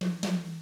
146BOSSAI2-L.wav